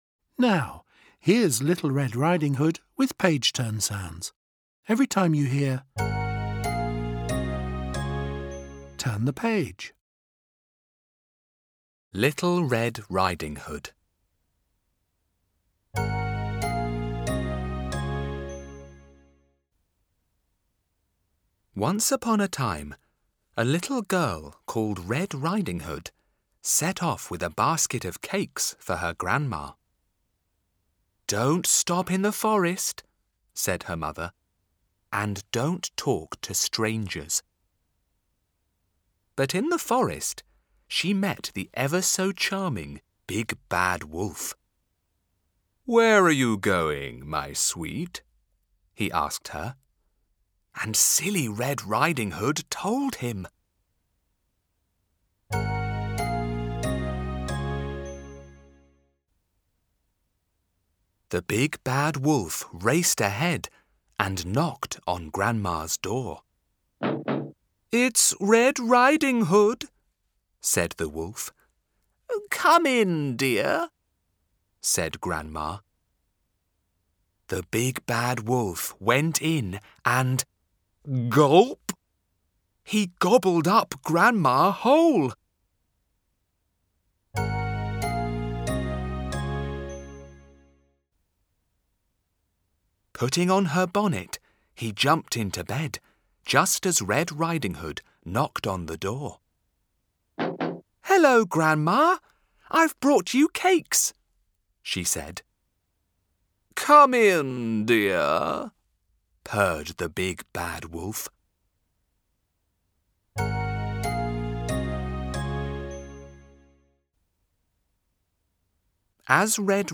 Little-Red-Riding-Hood-Read-Along-Audio.mp3